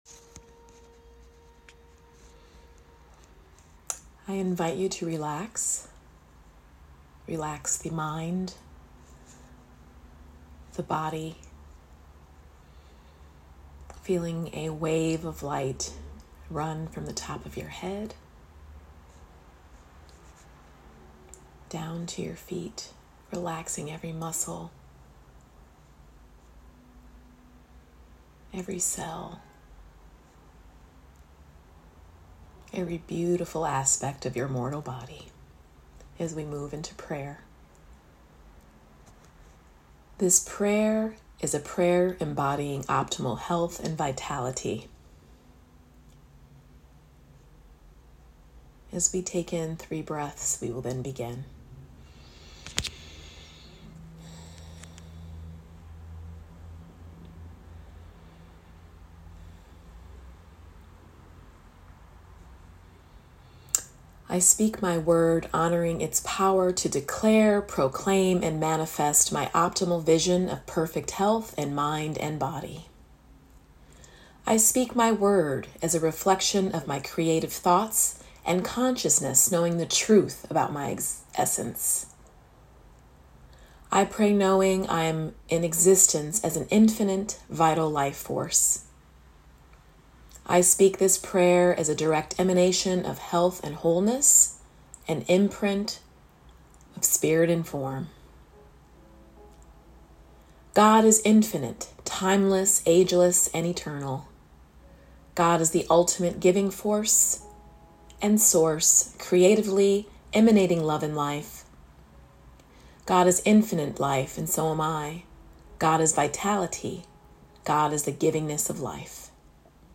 Recorded Prayers for Health and Wellness